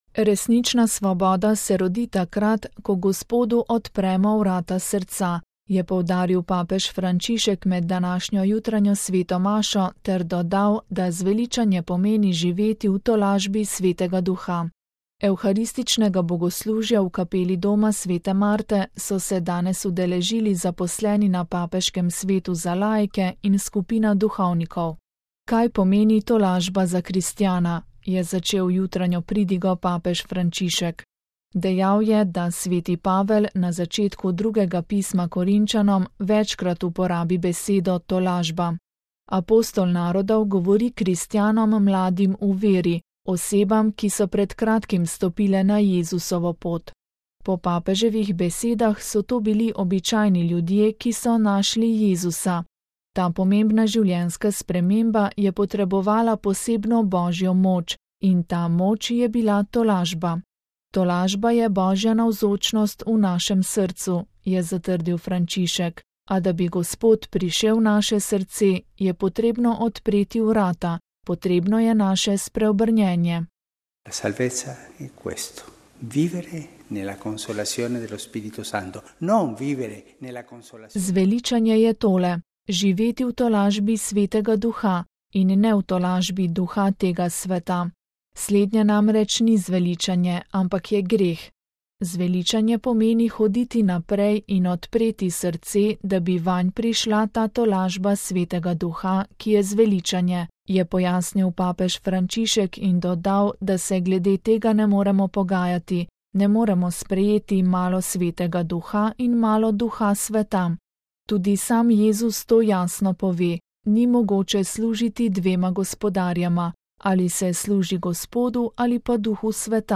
VATIKAN (ponedeljek, 10. junij 2013, RV) – Resnična svoboda se rodi takrat, ko Gospodu odpremo vrata srca, je poudaril papež Frančišek med današnjo jutranjo sveto mašo ter dodal, da zveličanje pomeni živeti v tolažbi Svetega Duha. Evharističnega bogoslužja v kapeli Doma sv. Marte so se danes udeležili zaposleni na Papeškem svetu za laike in skupina duhovnikov.